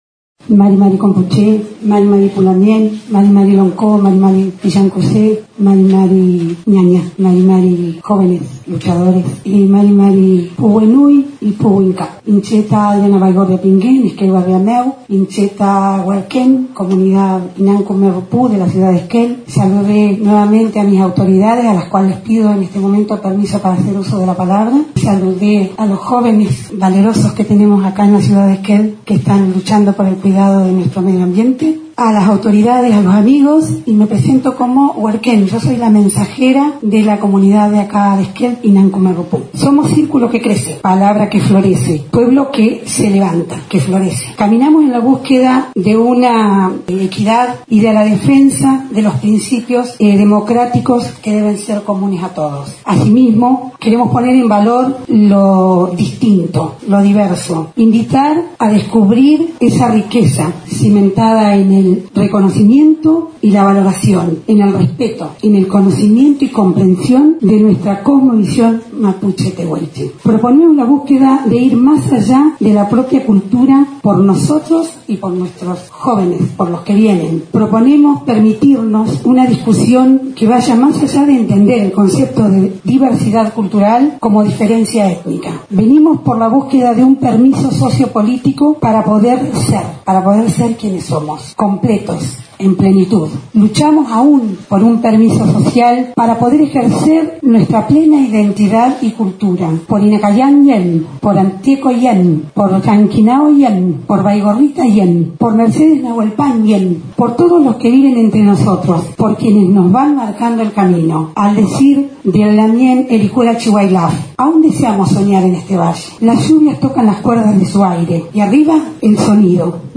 Durante la cuarta sesión ordinaria del período legislativo en el Concejo Deliberante, desde la organización del pueblo Mapuche Tehuelche Kume Rakizuam Compuche (obteniendo buenos conocimientos) y la Comunidad Mapuche Tehuelche Inam Küme Rupu de Esquel, presentaron un proyecto de ordenanza para que la ciudad de Esquel sea declarada municipio intercultural.